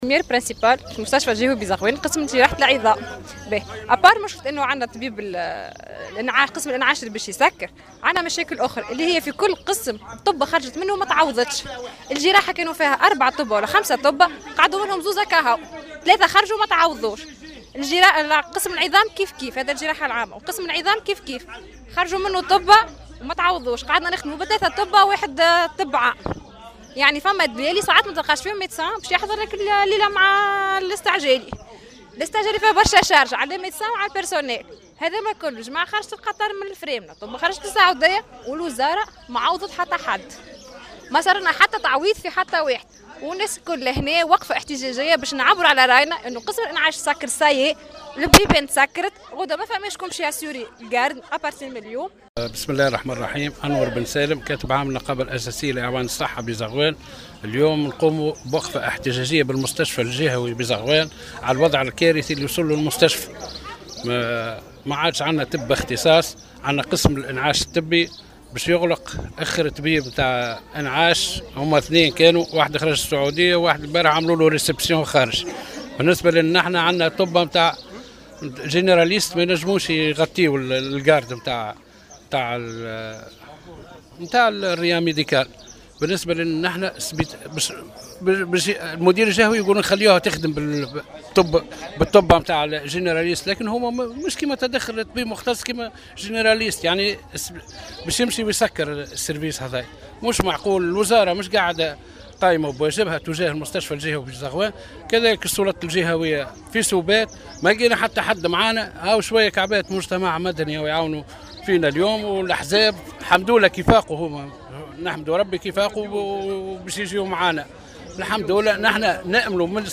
وقفة احتجاجية بمستشفى زغوان بسبب النقص الفادح في الأطباء